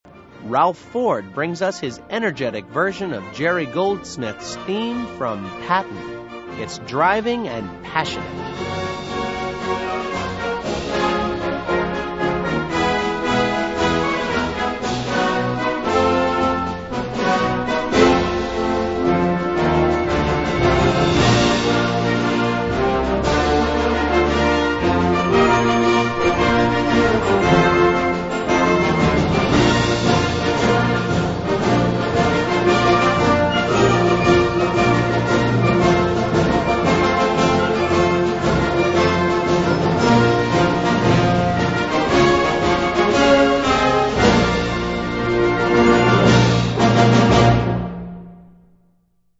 Commanding and bold
for Young Band
Blasorchester
charming march at the young band level